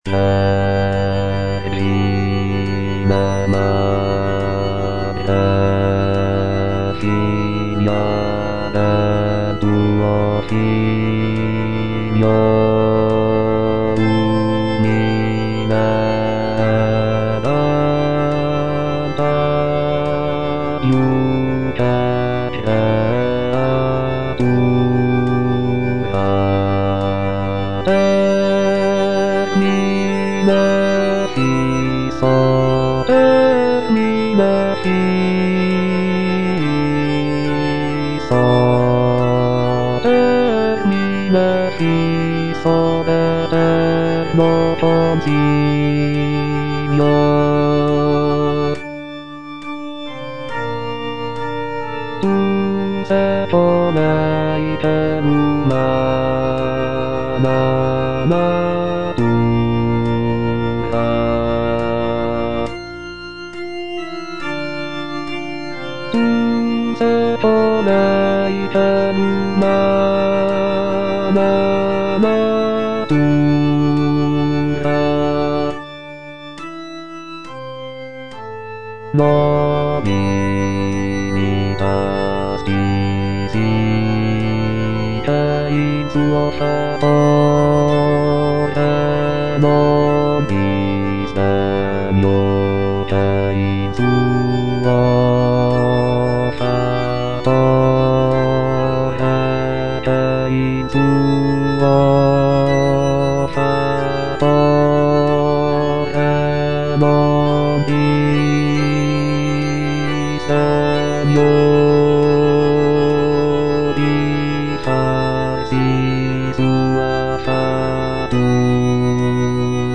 Bass (Voice with metronome)
choral work